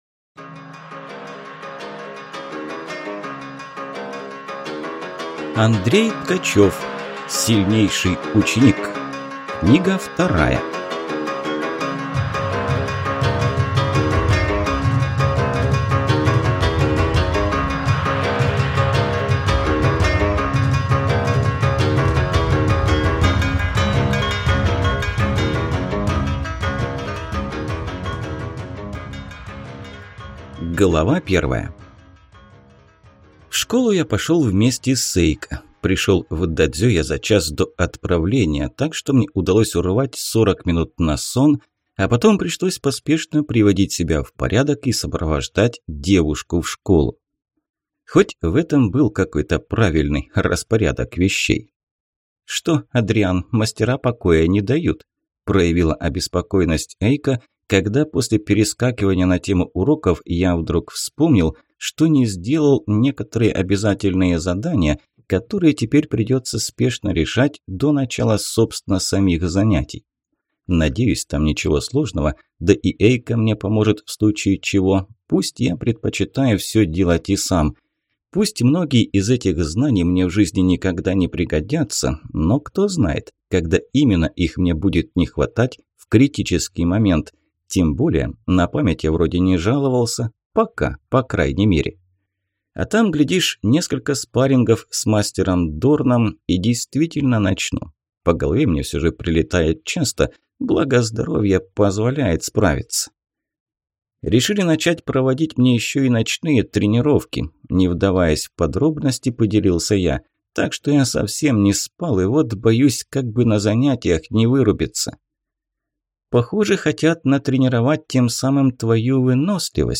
Аудиокнига Сильнейший ученик. Книга 2 | Библиотека аудиокниг